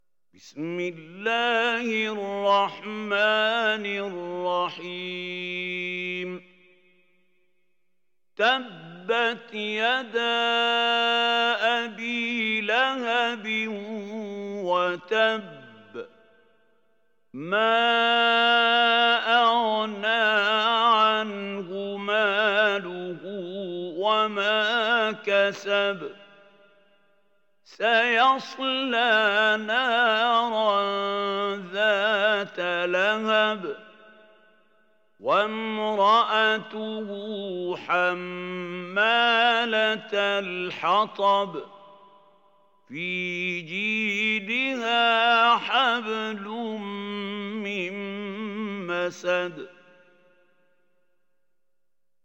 Sourate Al Masad Télécharger mp3 Mahmoud Khalil Al Hussary Riwayat Hafs an Assim, Téléchargez le Coran et écoutez les liens directs complets mp3